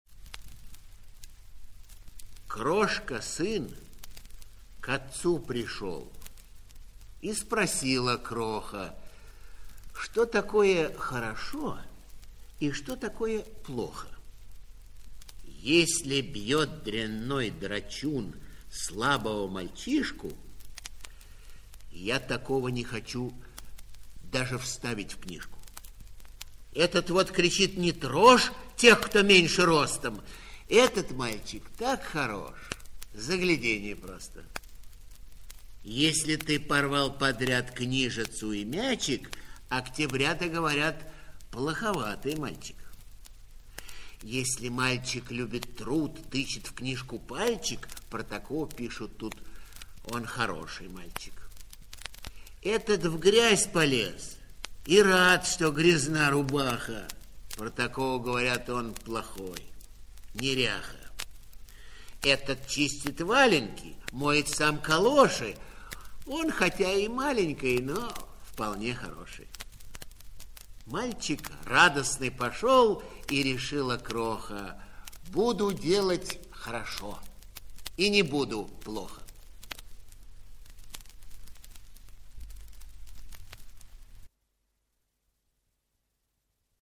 1. «Читай ухом здесь – Что такое хорошо и что такое плохо? (Владимир Владимирович Маяковский) читает И. Ильинский» /
uhom-zdes-Chto-takoe-horosho-i-chto-takoe-ploho-Vladimir-Vladimirovich-Mayakovskiy-chitaet-I.-Ilinskij-stih-club-ru.mp3